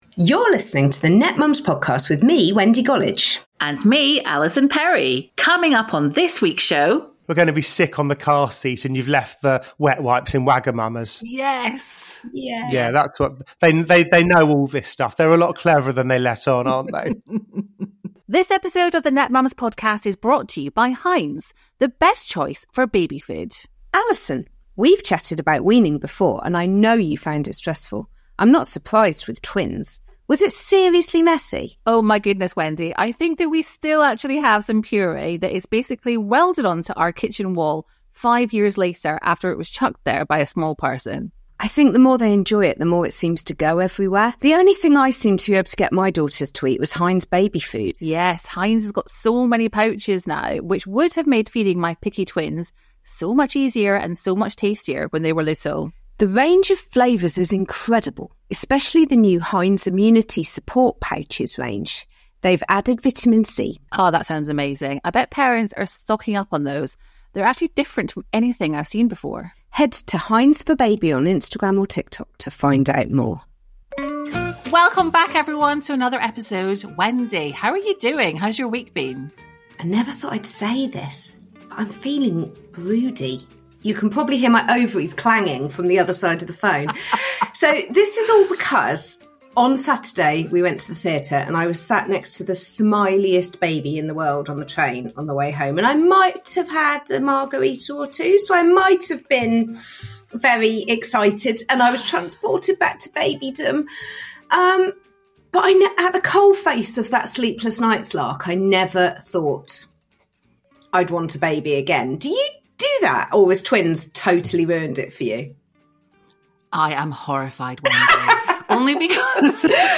Conversations